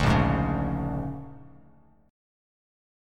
A#7#9 chord